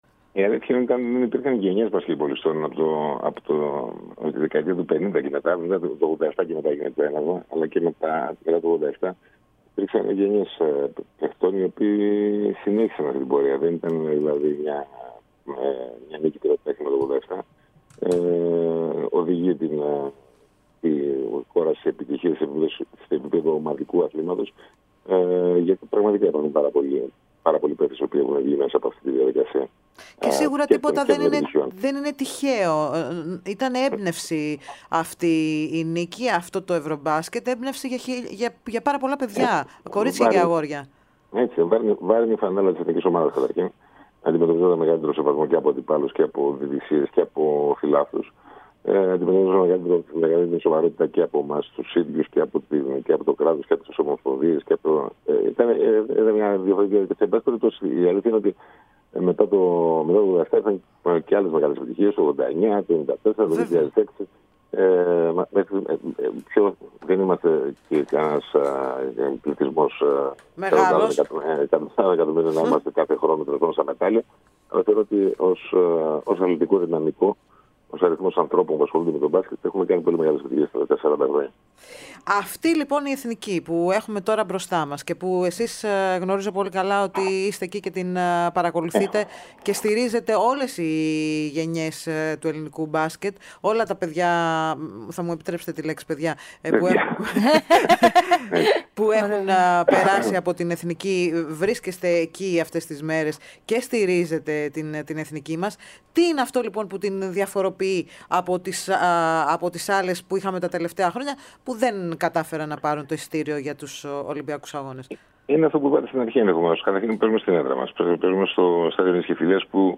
Ο σπουδαίος παλαίμαχος μπασκετμπολίστας εκδήλωσε τη στήριξη στην προσπάθεια της ομάδας και την πίστη πως θα βρεθεί στο μεγάλο ραντεβού του Παρισιού.